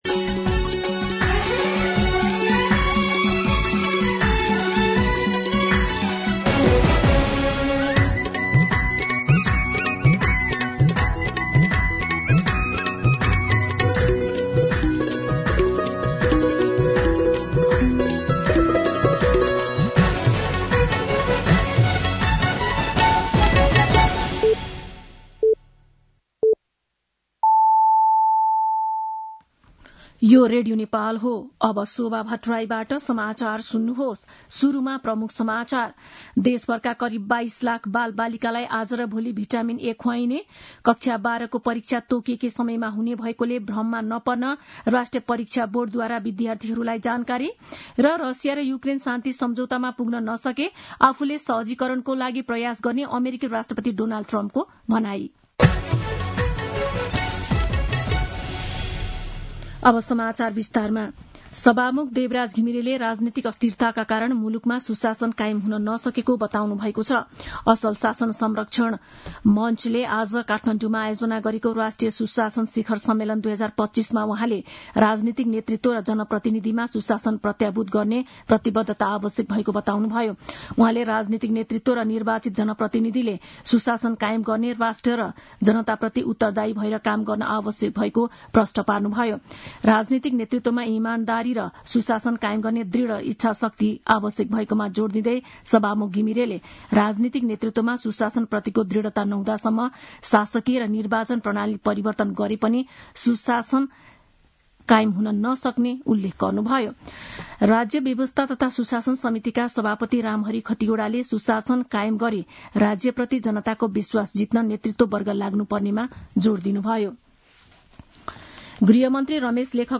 दिउँसो ३ बजेको नेपाली समाचार : ६ वैशाख , २०८२
3pm-Nepali-News-1.mp3